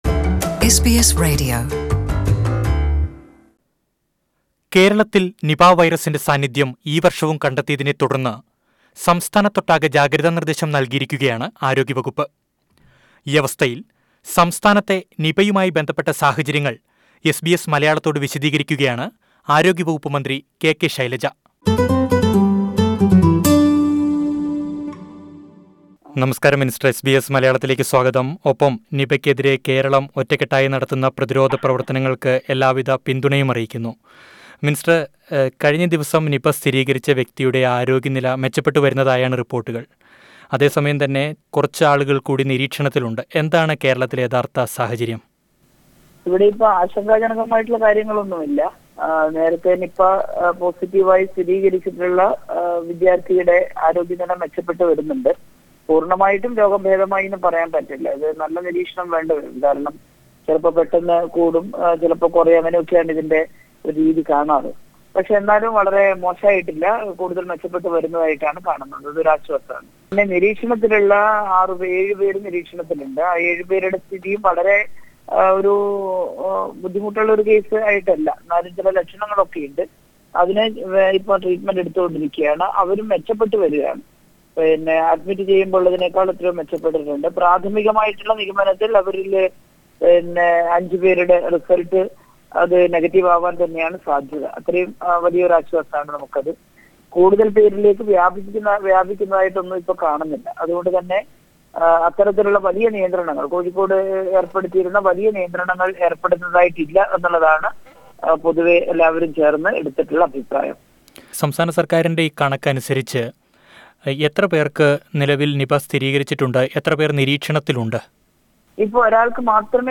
Kerala health minister K K Shailaja teacher said that the health department anticipated another Nipah virus outbreak in this season and was prepared to fight that. She explained the situation in this interview to SBS Malayalam